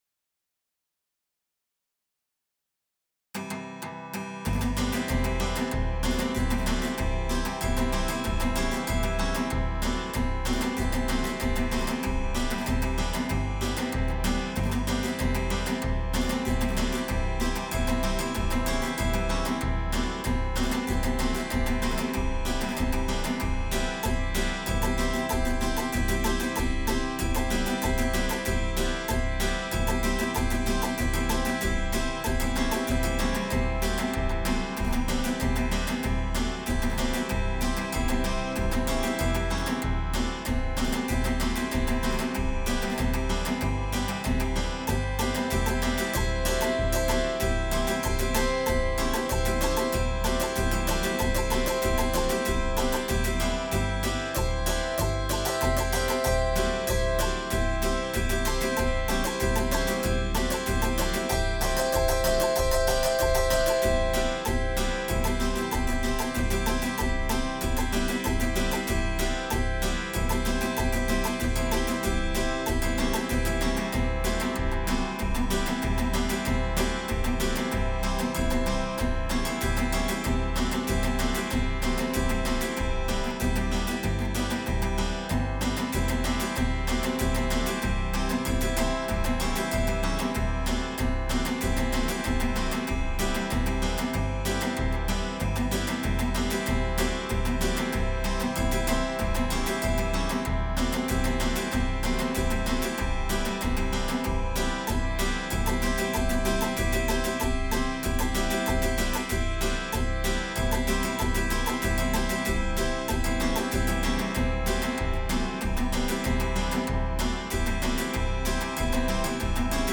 autoharp-mit-chords.mp3